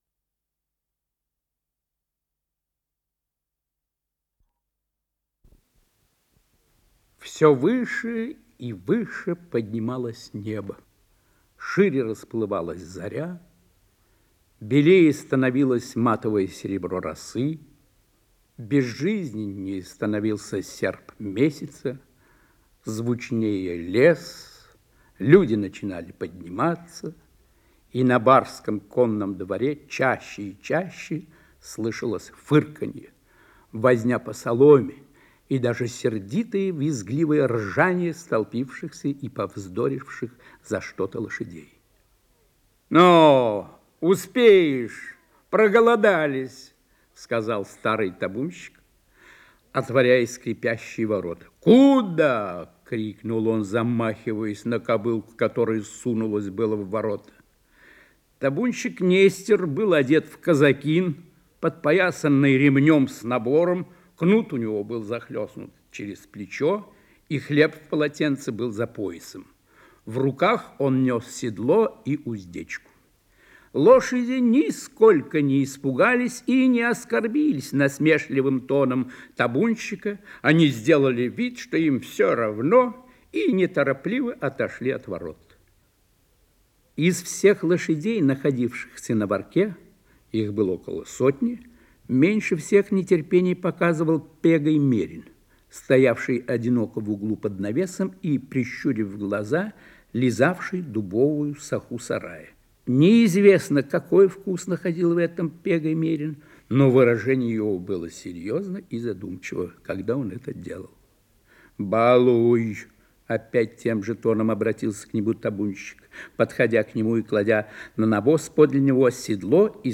Исполнитель: Евгений Лебедев - чтение
Повесть, передача 1-я